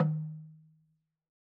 Percussion
LogDrumHi_MedM_v3_rr1_Sum.wav